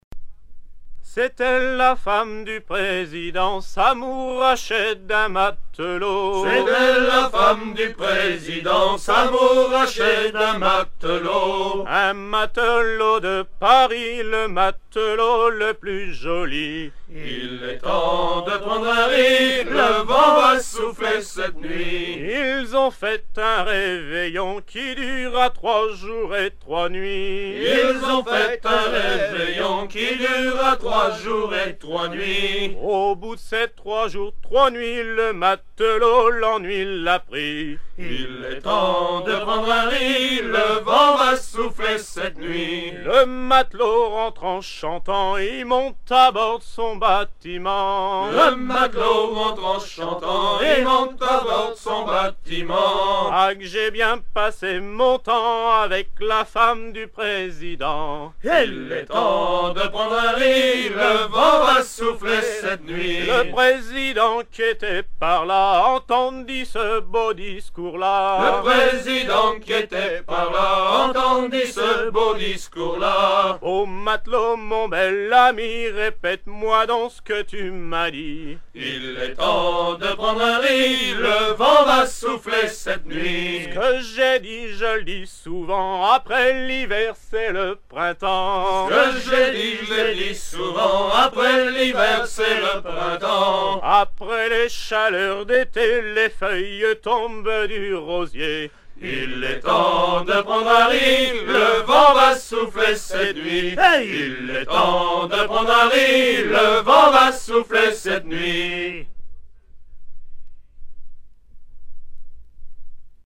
Version recueillie en 1975
danse : ronde
Genre strophique
Pièce musicale éditée